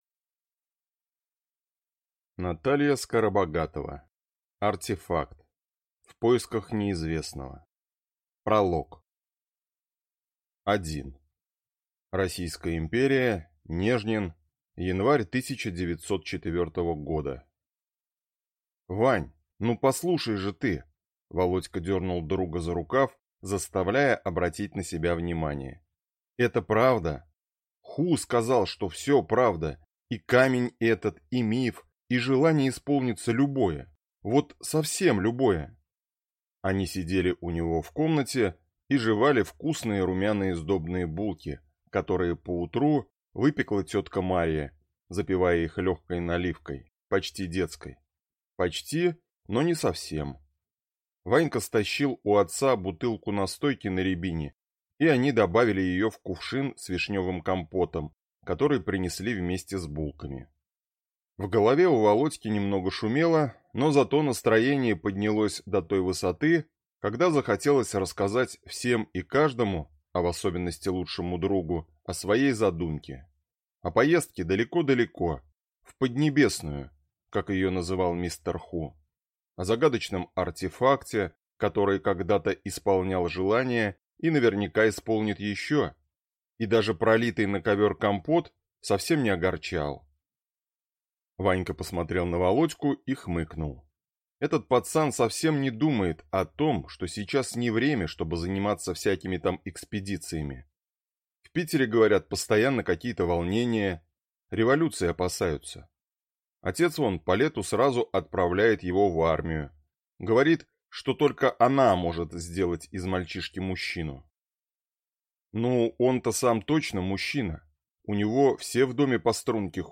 Аудиокнига Артефакт. В поисках неизвестного | Библиотека аудиокниг